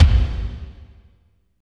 36.04 KICK.wav